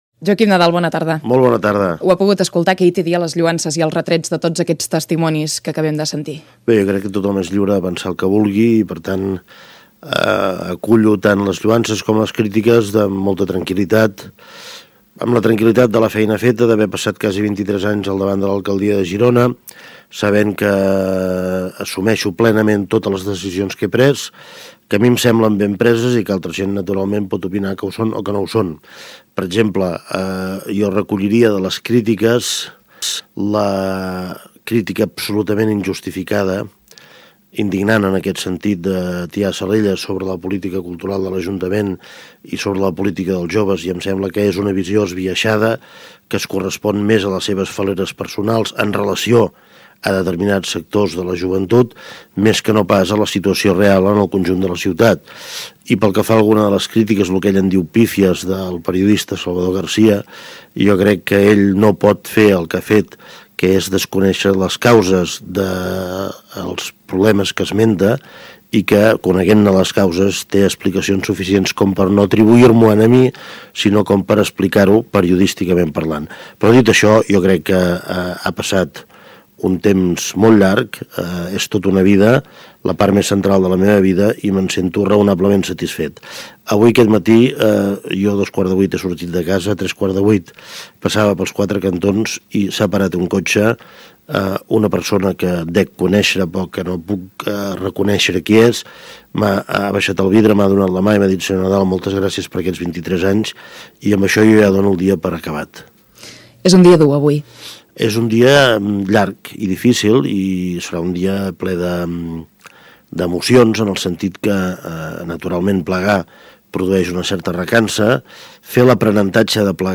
Entrevista Joaquím Nadal - Ràdio Girona, 2002